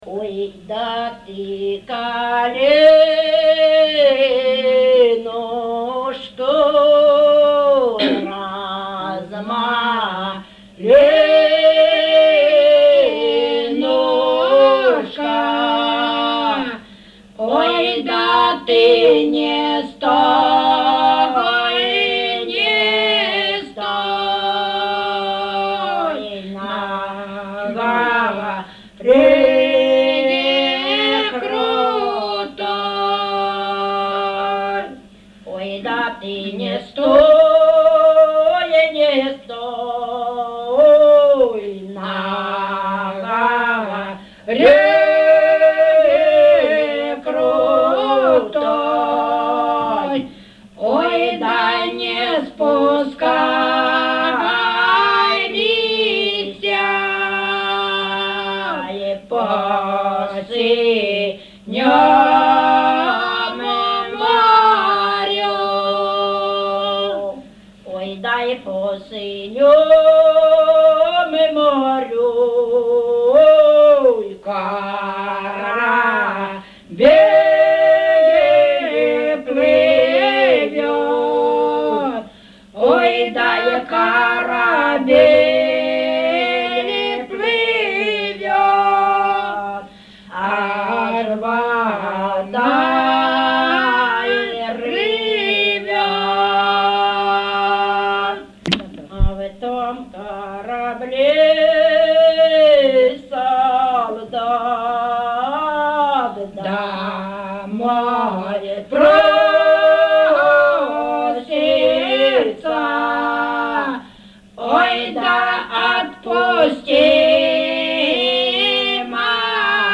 ЖанрПісні з особистого та родинного життя, Солдатські
МотивСлужба у війську, Журба, туга
Місце записум. Старобільськ, Старобільський район, Луганська обл., Україна, Слобожанщина